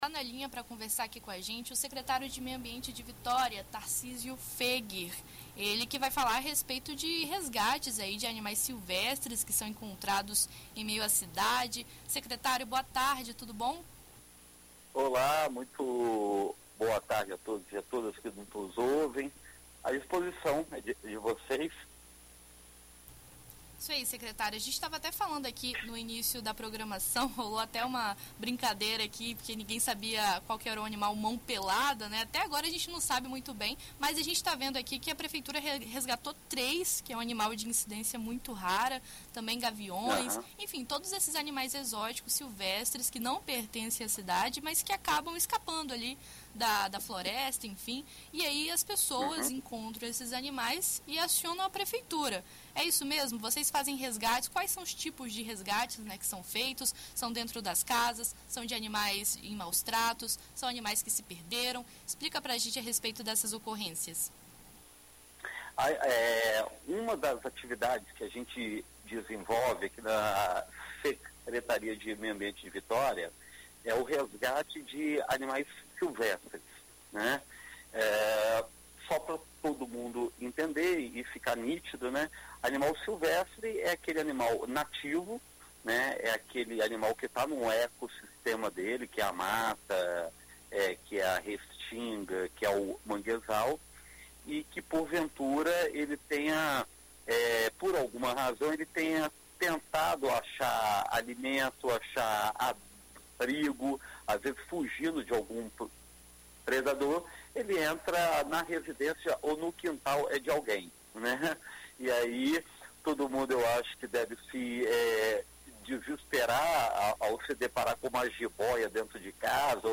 Em entrevista à BandNews FM ES nesta quarta-feira (15), o Secretário de Meio Ambiente de Vitória, Tarcísio Foeger, fala sobre as ocorrências, orientações e resgates de animais silvestres em Vitória.